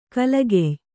♪ kalagey